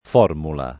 f0rmula] (oggi raro formola [f0rmola]) s. f. — quasi solo -mul- nel dim. -etta e nei der. -abile, -are (verbo), -ato, -azione — regolare, in parola di formaz. dòtta, la pn. aperta dell’-o-, domin. in tutta la Tosc. e più ancóra a Fir.; spiegabile con l’attraz. di forma la pn. chiusa, discretam. diffusa nelle regioni confinanti